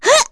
Erze-Vox_Jump.wav